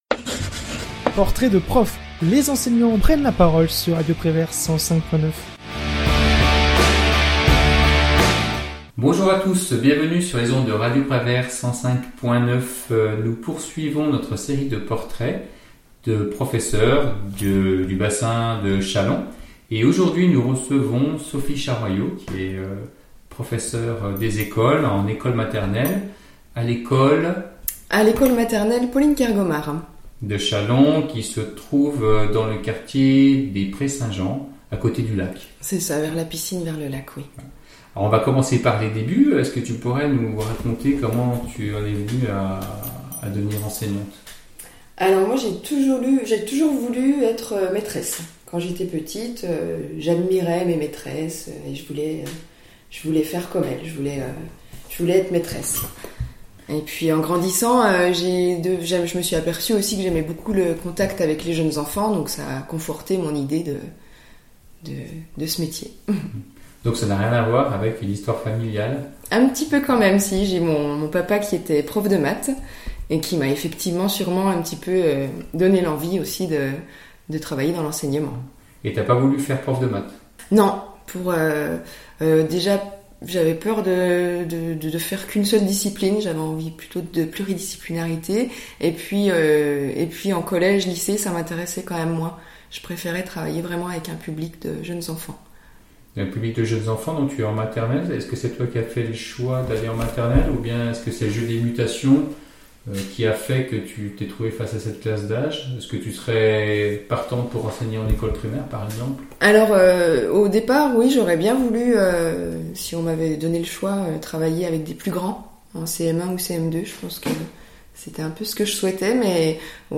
L'Interview de Radio Prévert / Émissions occasionnelles Podcasts